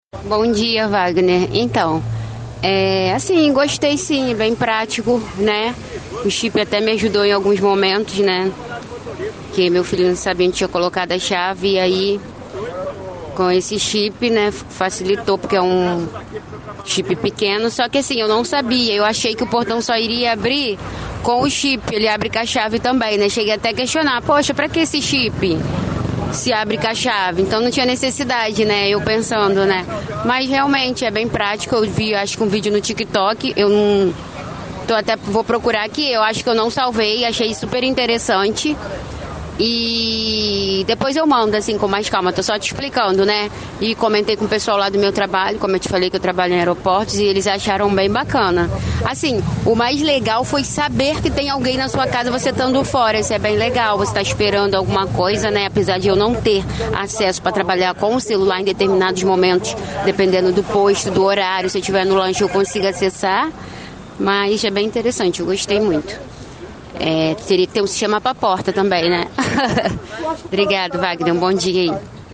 DEPOIMENTO DE VOZ DE CLIENTES